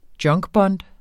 Udtale [ ˈdjʌŋgˌbʌnd ]